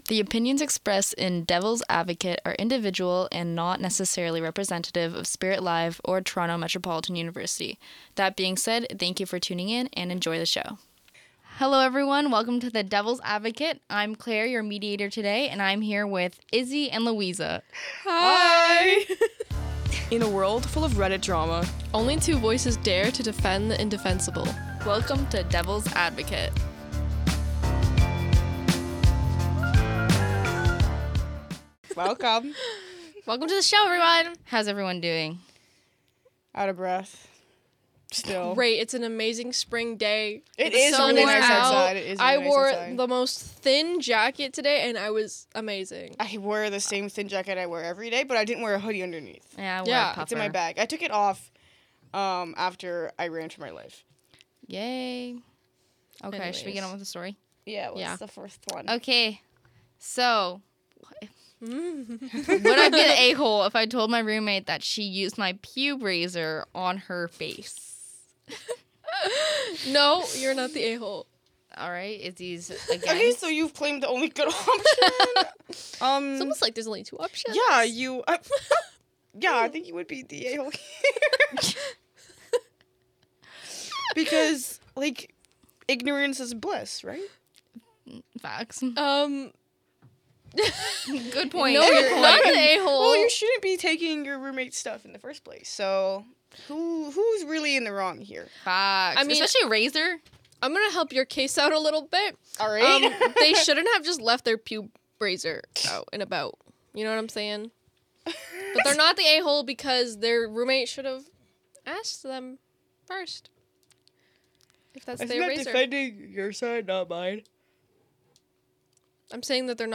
Using Reddit “Am I the Asshole?” posts, one host has to defend the person while the other argues against them, no matter their real opinion. It’s part comedy, part debate, and part chaos.